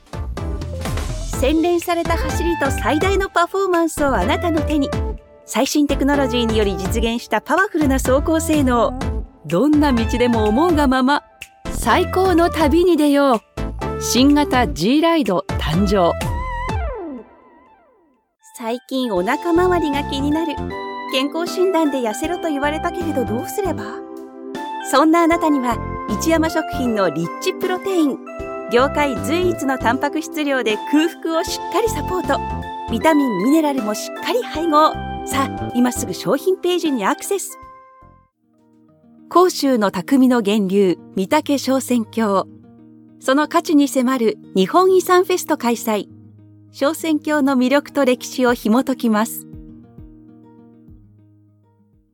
Natürlich, Vielseitig, Zuverlässig, Warm, Sanft
Kommerziell
Her voice is known for its empathetic and believable quality, making it ideal for connecting with audiences on a deeper level.